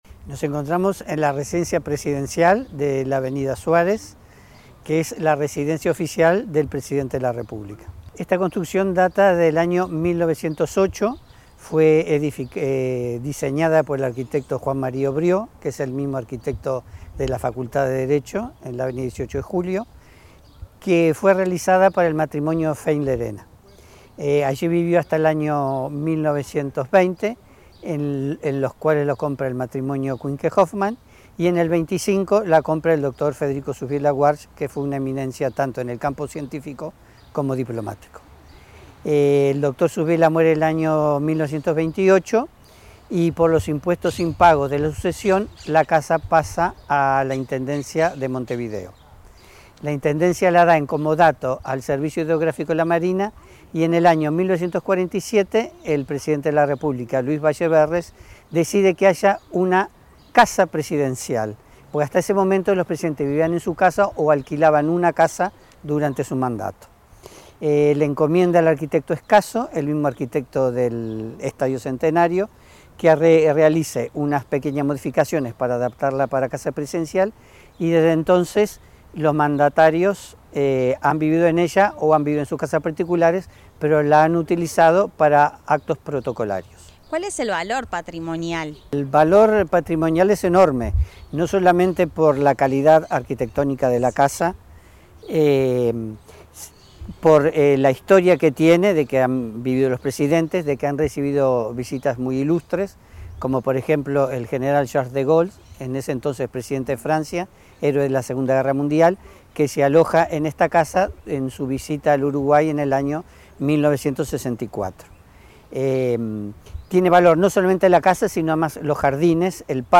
Declaraciones
brindó declaraciones en el marco de las actividades del Día del Patrimonio 2025.